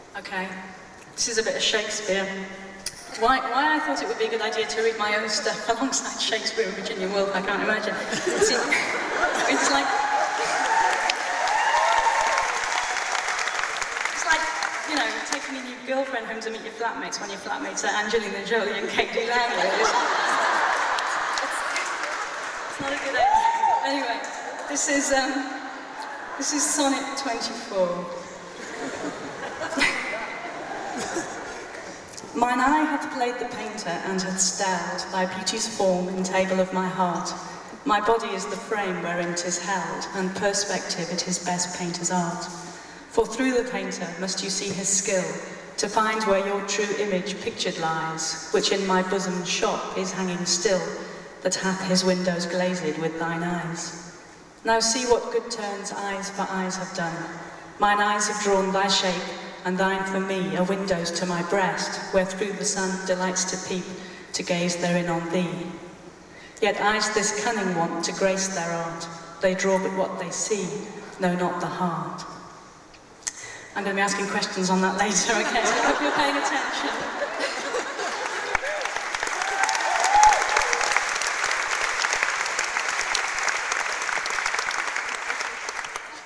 lifeblood: bootlegs: 2002-08-03: union chapel - london, england
11. reading of shakespeare's sonnet 24 - sarah waters (1:26)